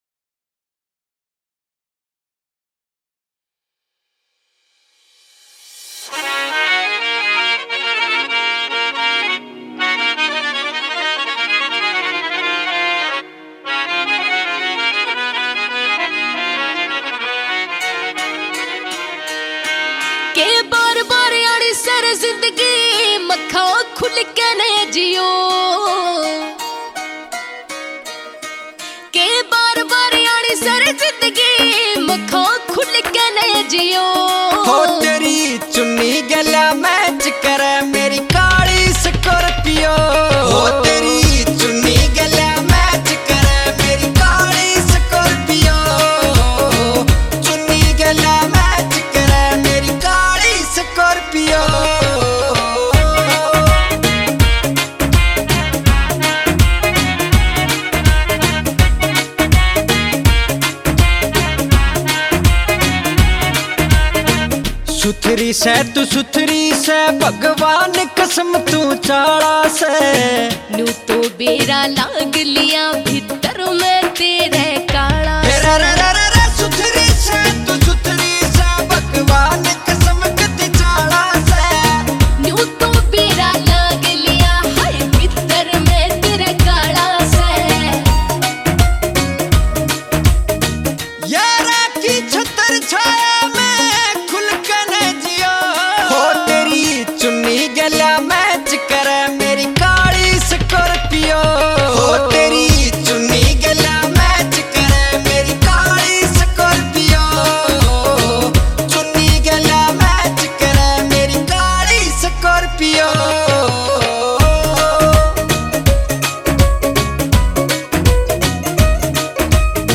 Category New Punjabi Song 2023 Singer(s